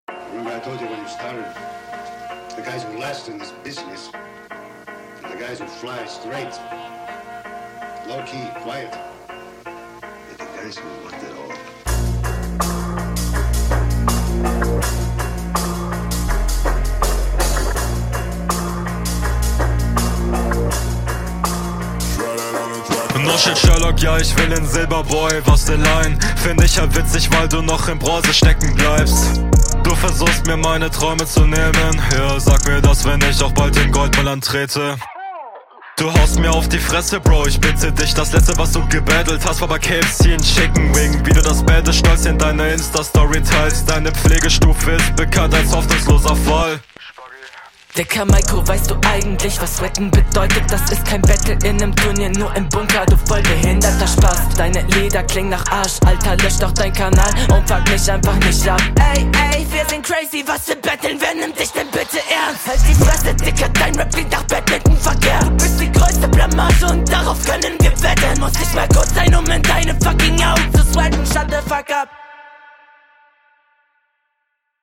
Mix klingt hier irgendwie viel weaker als letzte Runde.